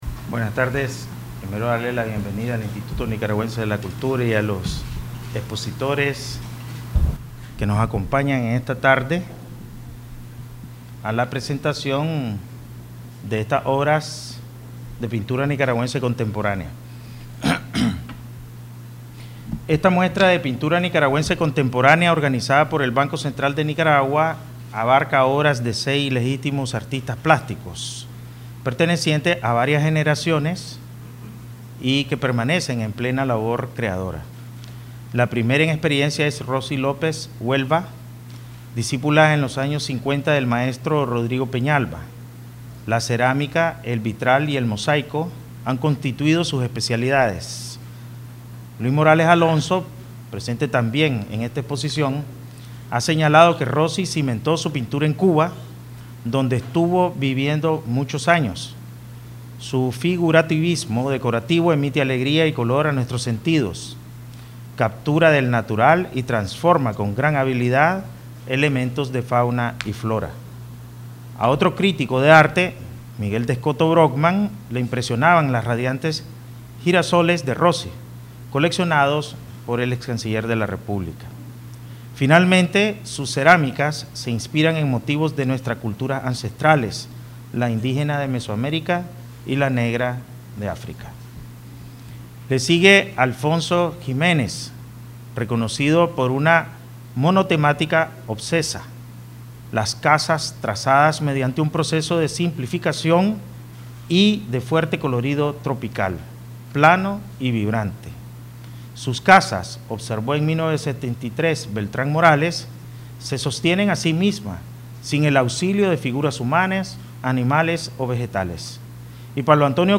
Palabras del Presidente del BCN, Ovidio Reyes R., inauguración Exposición de Pintura Contemporánea Nicaragüense
Managua, 11 de septiembre 2018